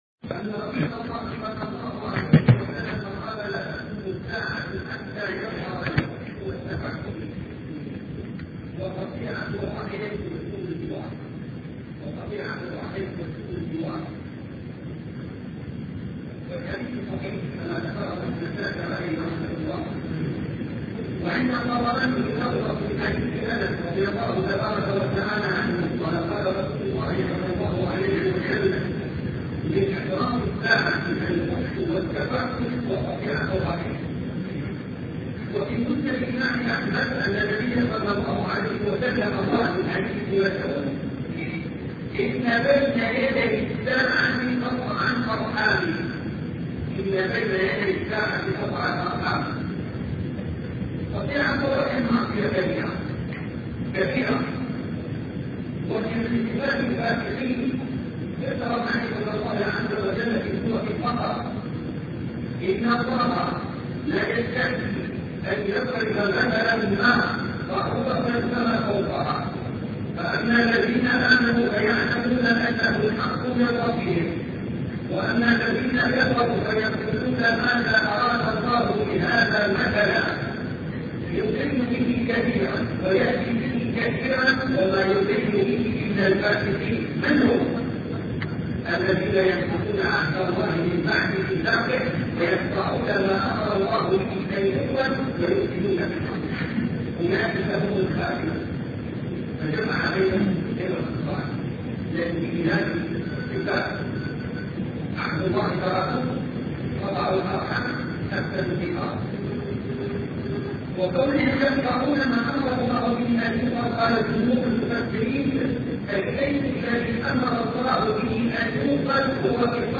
سلسلة محاضرات أشراط الساعة الوسطئ